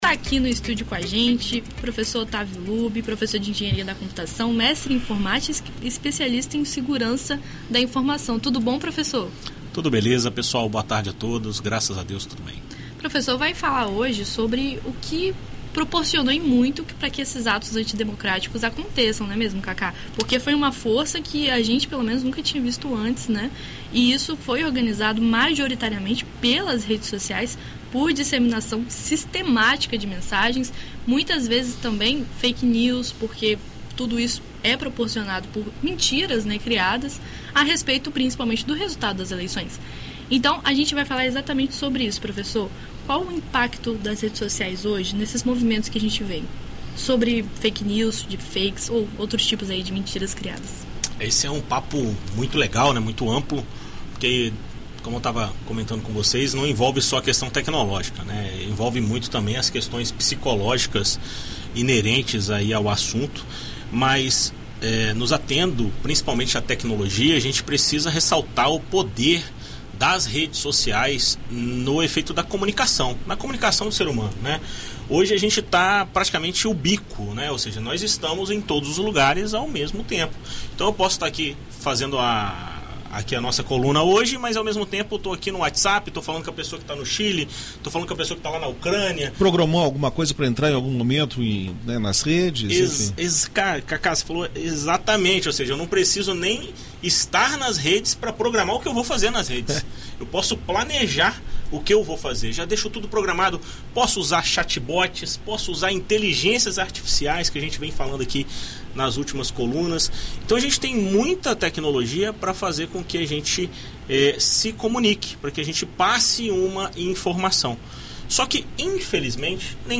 Em entrevista à Band News FM ES